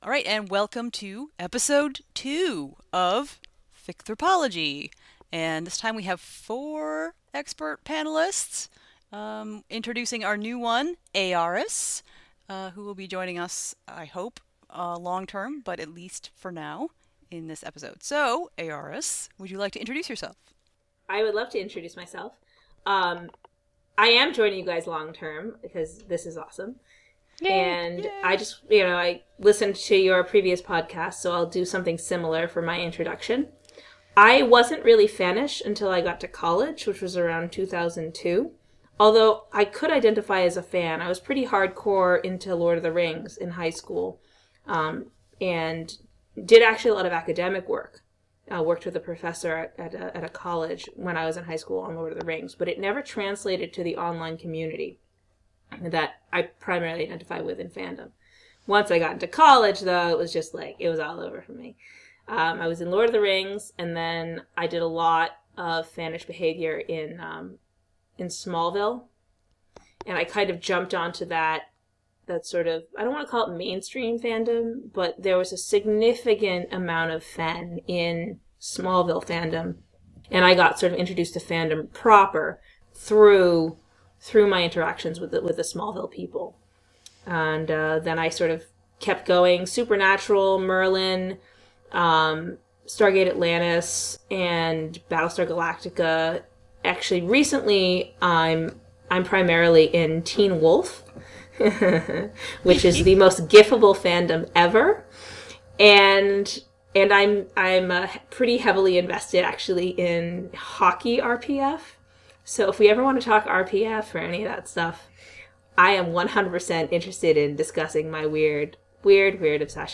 The podcast Ficthropology is designed as a discussion of the wonderful world of fan fiction from a critical standpoint. Modeled after an informal academic discussion group, we generally tackle one article, book, or theme per episode, from any field from anthropology, media studies, communications, women's studies - whoever has an interesting take of fanfic, its writers, readers, community, genres, themes, trends, or tropes.